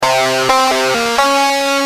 Lead_a6.wav